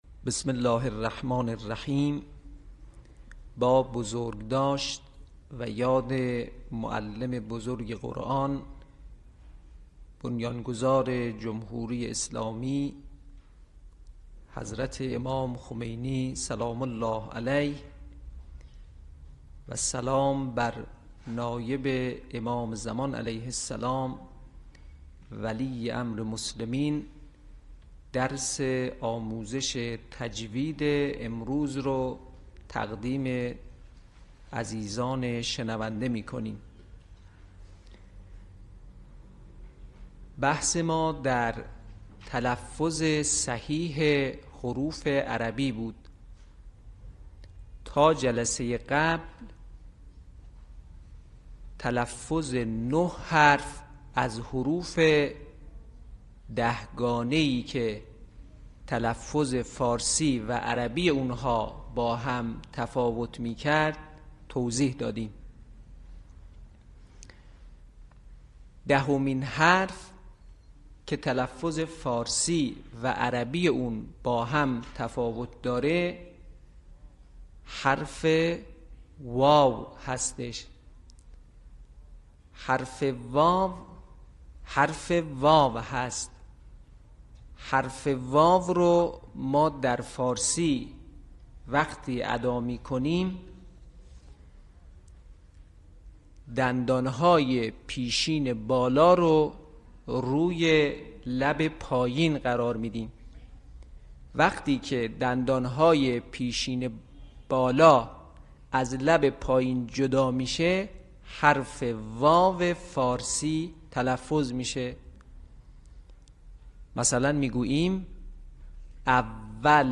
صوت | آموزش تلفظ حرف واو